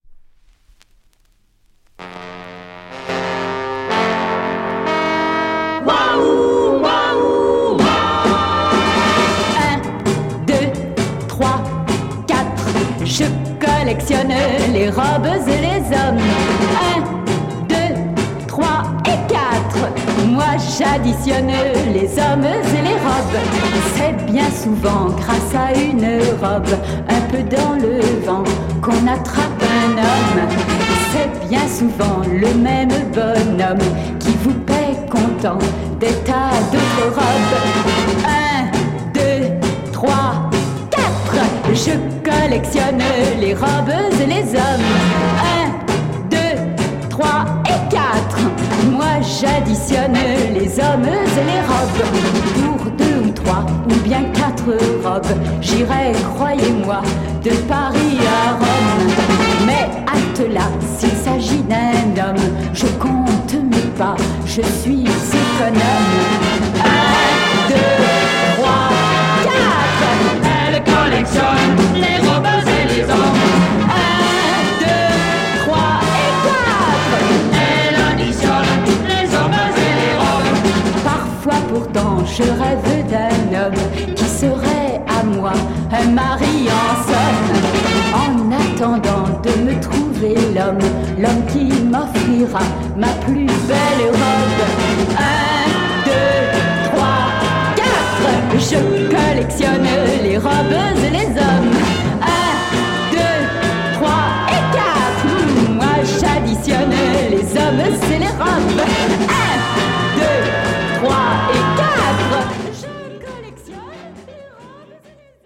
Rare French lolita yéyé EP
Very rare little French lolita yeye EP